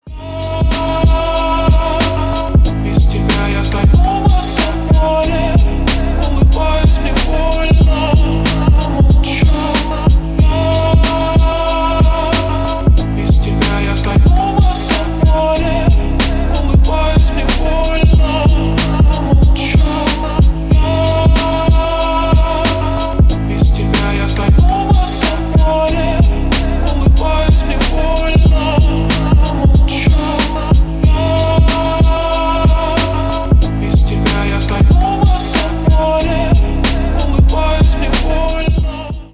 Поп Музыка
громкие # клубные